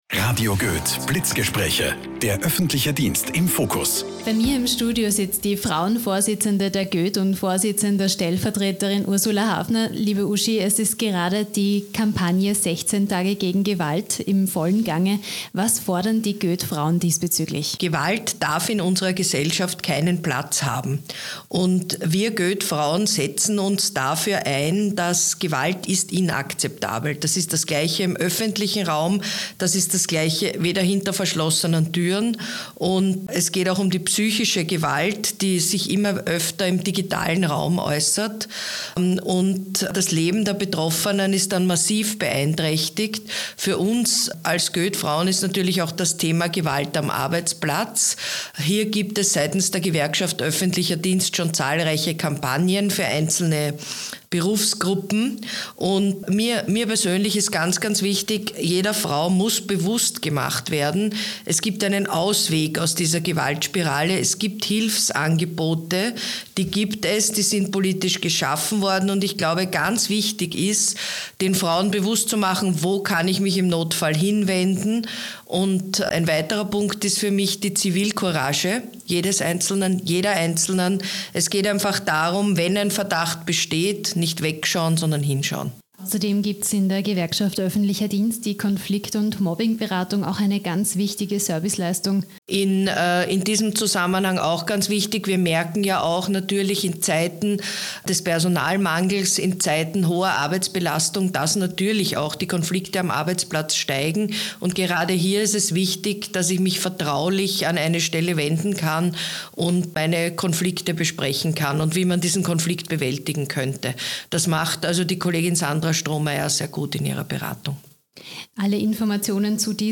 Blitzgespraech_16_Tage_gegen_Gewalt.mp3